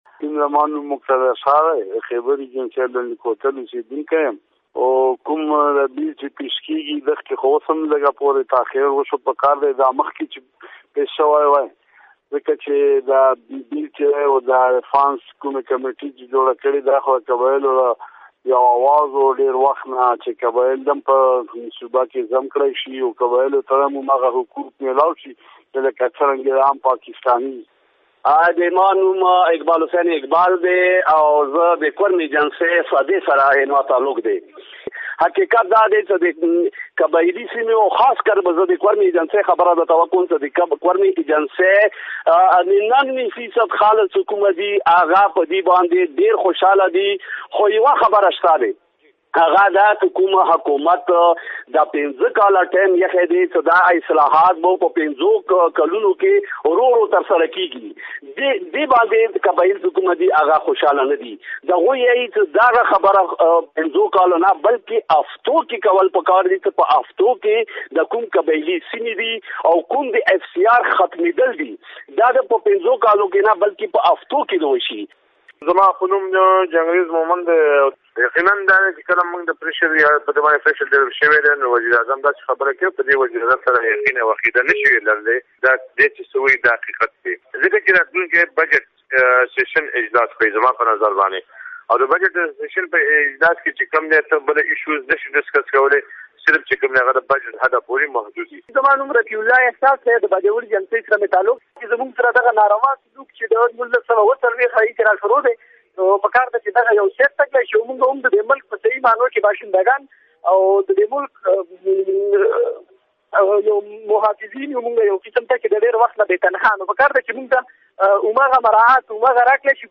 خو ګڼ شمیر قبایل بیا دا نیوکه هم کوي حکومت د اصلاحاتو عملي کولو موده یا وخت پينځه کاله ښودلی کوم چې سوا دی او حکومت له پکار ده دغه کار زر تر زره عملي کړي. د فاټا اصلاحاتو په اړه شوې نني ټول ګوندیز کانفرنس وروستو د پاکستان قبایلي سیمو ځنو غړو خپل غبرګون ډیوه ریډیو سره شریک کړی تاسو يې په لاندیني لنک اوریدی شۍ.